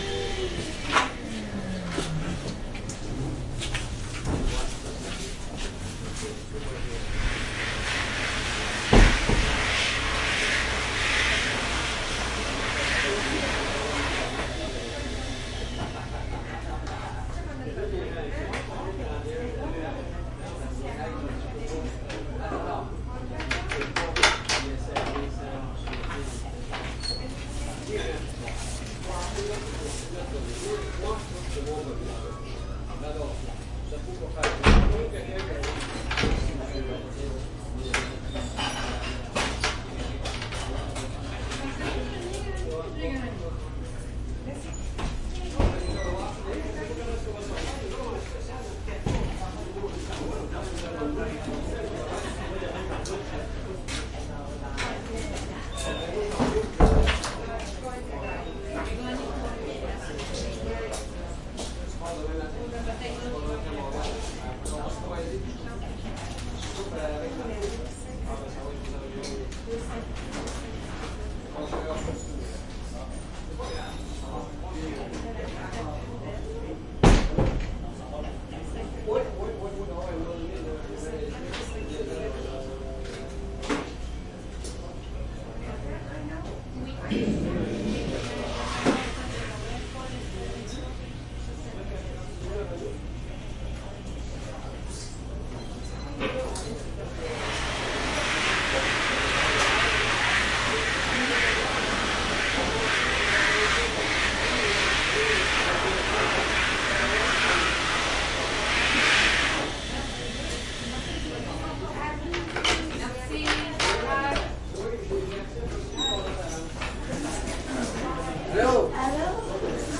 蒙特利尔 " 人群中的繁忙餐厅 加拿大蒙特利尔
Tag: 蒙特利尔 INT 餐厅 人群 加拿大